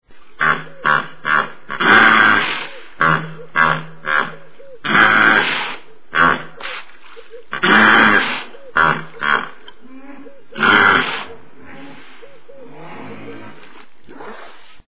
Они выражают их с помощью рева, иногда звуки похожи на конское ржание или хрюканье. Рев бегемотов очень громкий, разносится далеко по африканским просторам.
gippopotam-ili-begemot-hippopotamus-amphibius.mp3